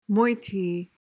PRONUNCIATION: (MOI-tee) MEANING: adjective: Containing moits -- foreign particles in wool, such as straw or bark.